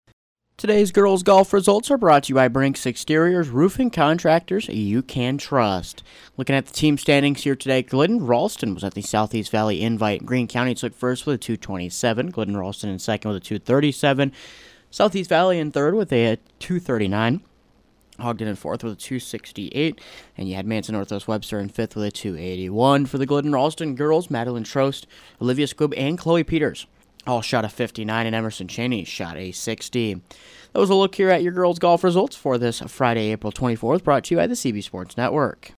Below is an Audio Recap of girls’ golf results from Friday, April 24th